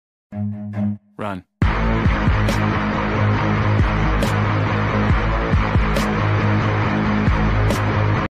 Run Meme Sound Effect sound effects free download